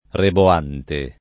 reboante [
rebo#nte] (meno bene roboante [robo#nte]) agg.